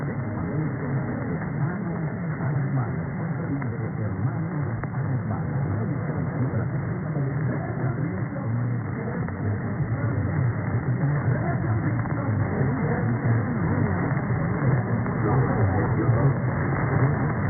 unIDjingle660kHz.mp3